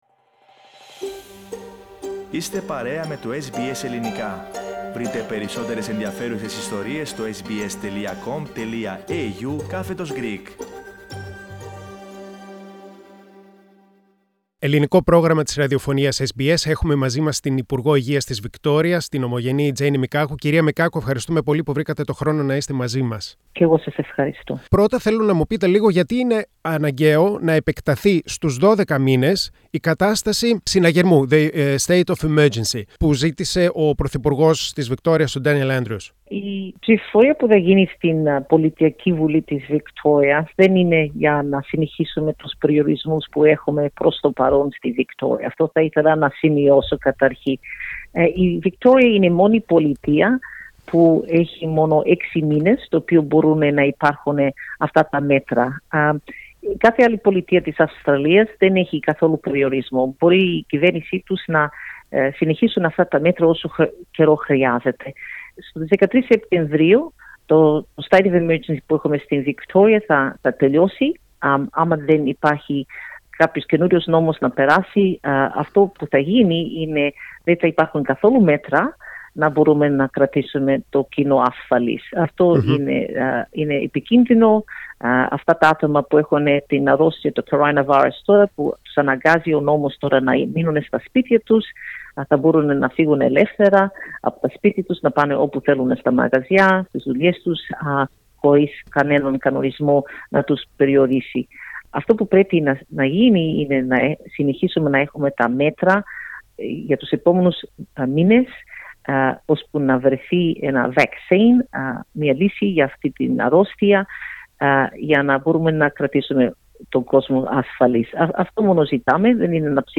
Η ομογενής υπουργός Υγείας της Βικτώριας, Τζένη Μικάκου μιλά στο SBS Greek απαντά στην κριτική που δέχεται η κυβέρνησή της.